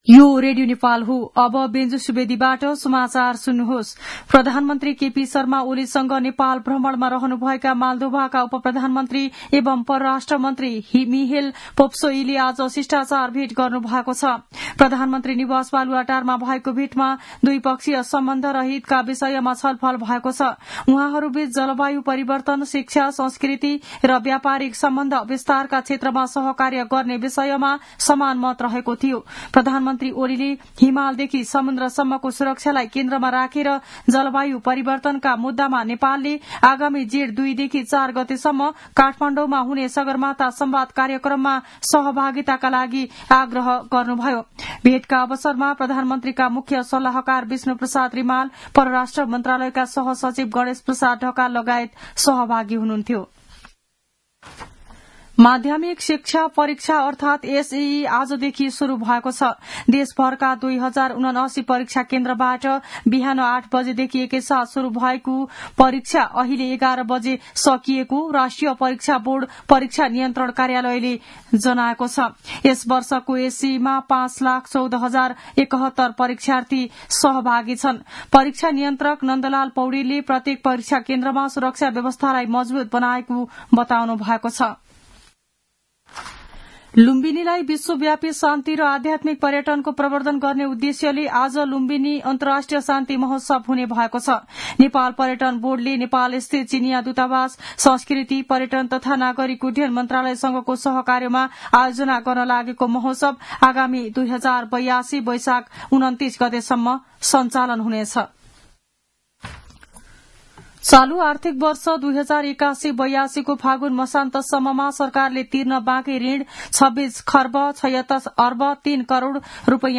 मध्यान्ह १२ बजेको नेपाली समाचार : ७ चैत , २०८१